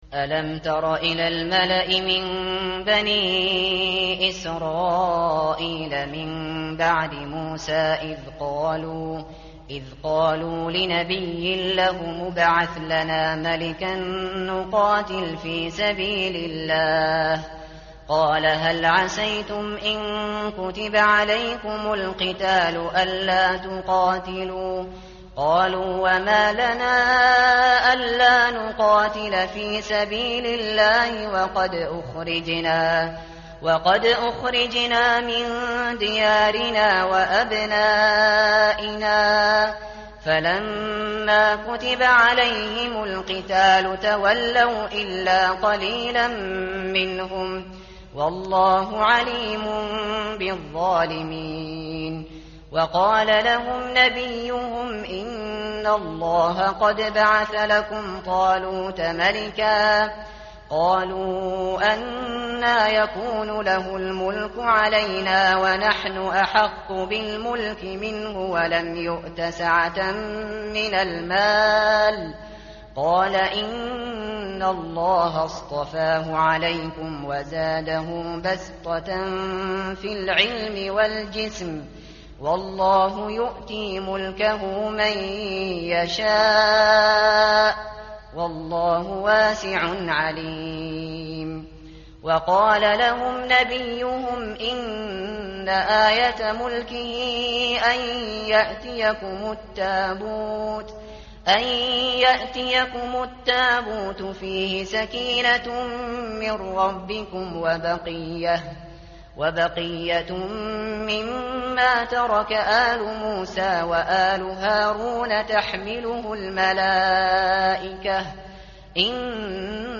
tartil_shateri_page_040.mp3